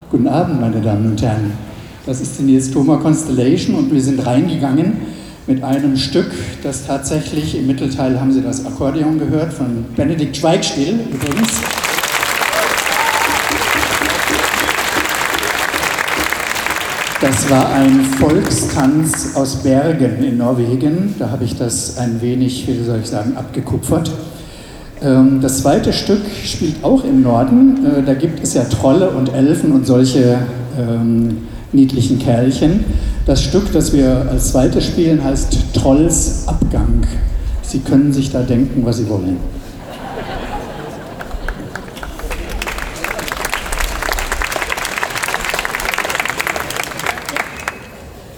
Konzert Regionalabend 31. Jazz im Brunnenhof (Trier)
03 - Ansage.mp3